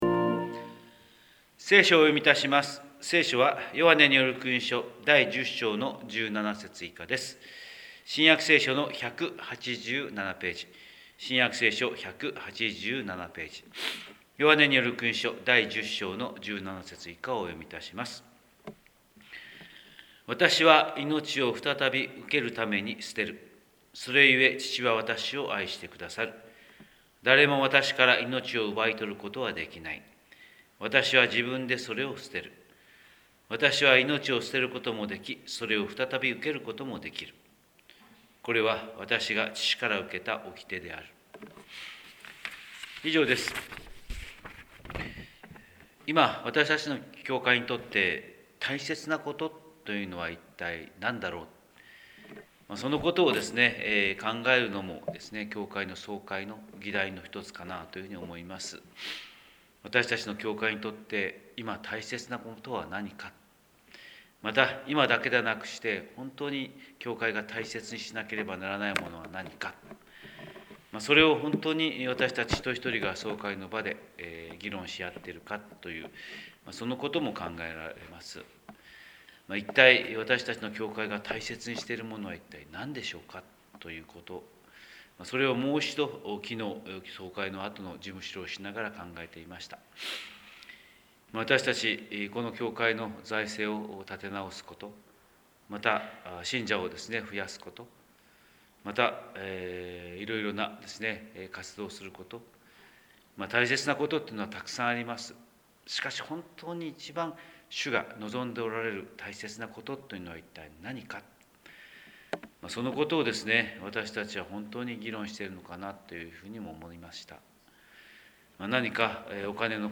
神様の色鉛筆（音声説教）: 広島教会朝礼拝250204
広島教会朝礼拝250204「大切な事とは」